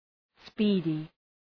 Shkrimi fonetik {‘spi:dı}